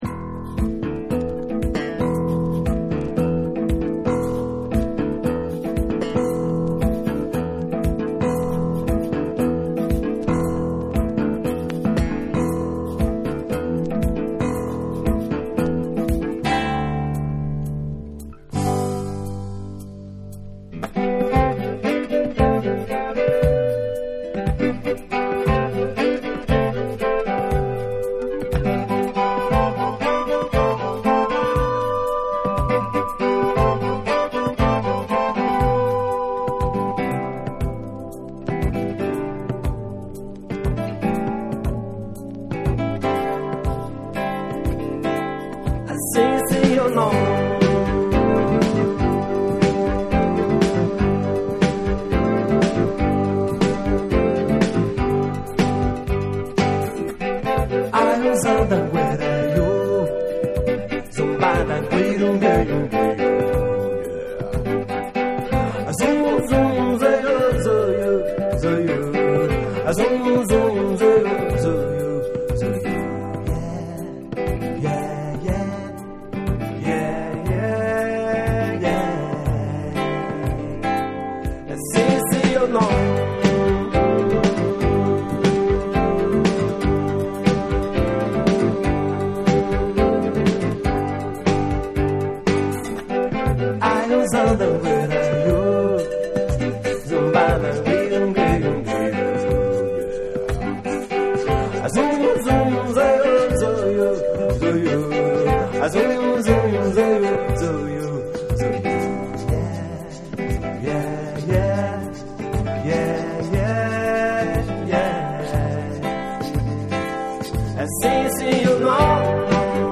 WORLD / CUMBIA